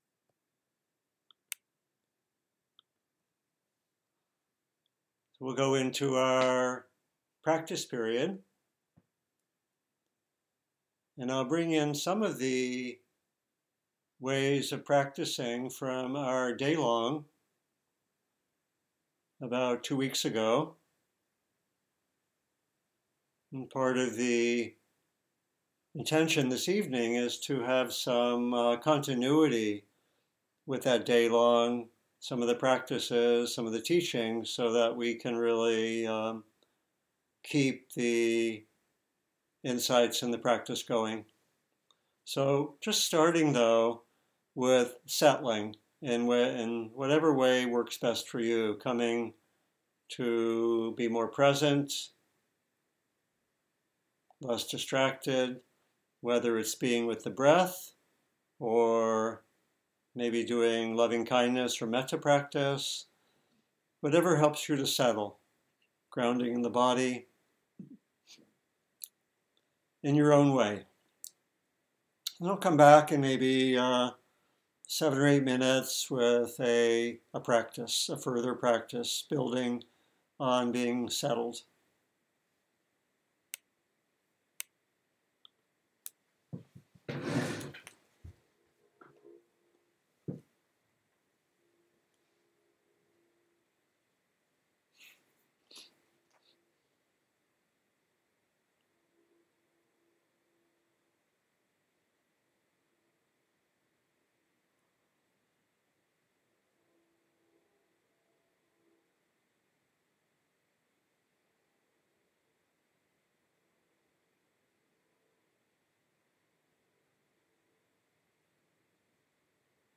Guided Meditation on Feeling-Tone (Pleasant, Unpleasant, or Neutral) and Reactivity.